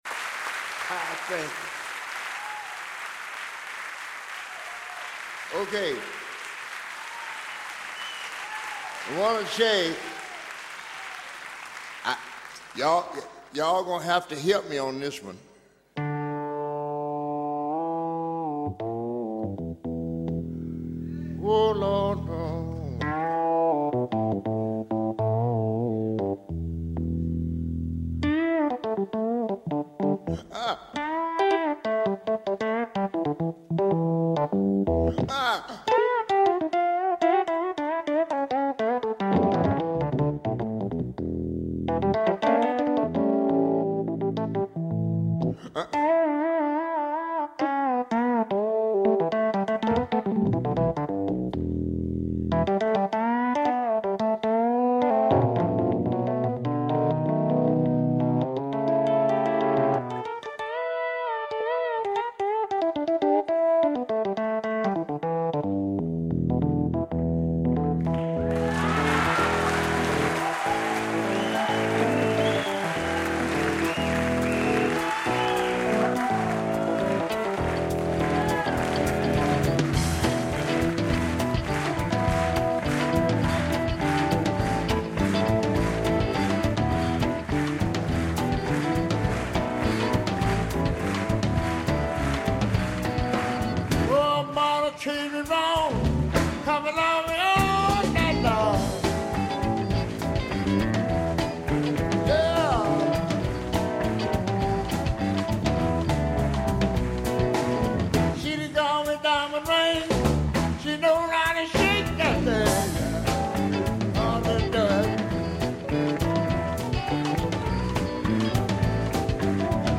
Le Soul Jazz